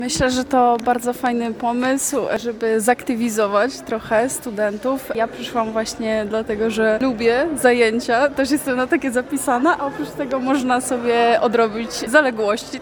Przyszłam, ponieważ jestem zapisana na zajęcia fitness i naprawdę je lubię – mówiła jedna z uczestniczek.
uczestniczka-fitness.mp3